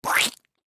splat-v4.ogg